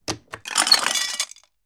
CandyMachineKnob LB011101
Candy Machine; Knob Motion And Clunky Candy Falls Into Metal Basin.